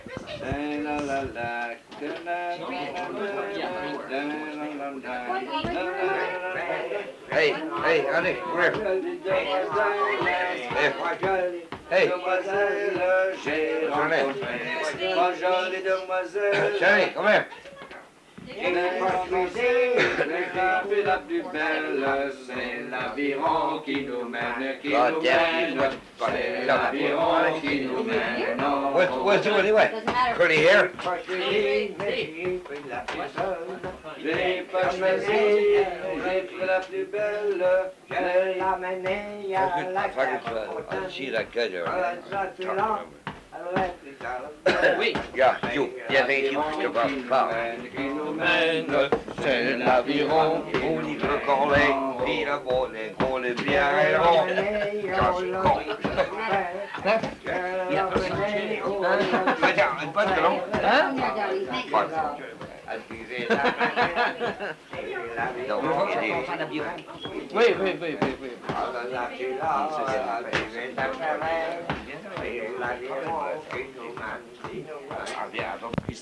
Chanson Item Type Metadata
Emplacement L'Anse-aux-Canards